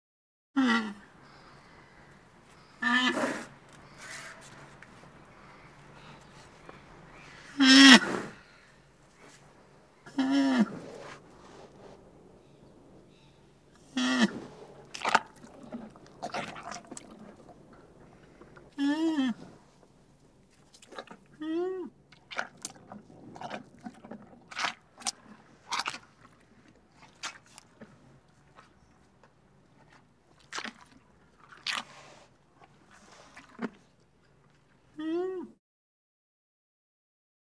Rhinoceros Snorts, Eating. Multiple Calls, Snorts And Eating Sounds. Medium Perspective.